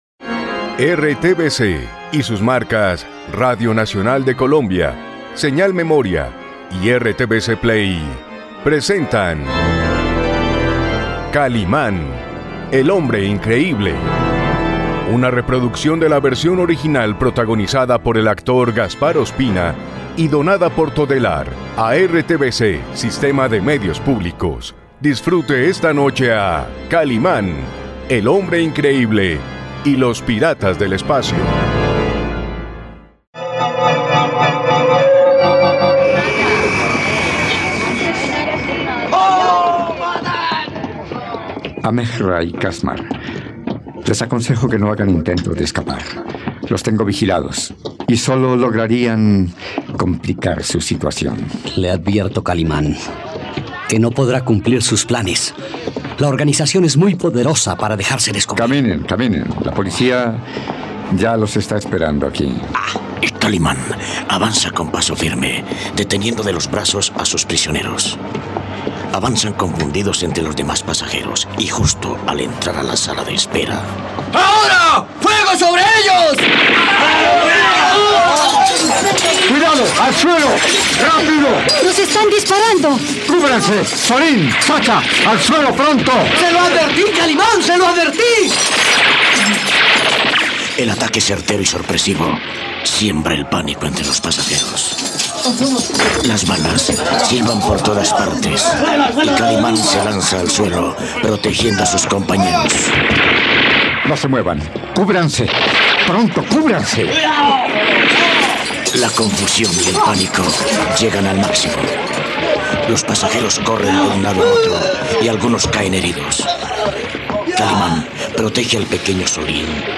No te pierdas la radionovela de Kalimán y los piratas del espacio aquí, en RTVCPlay.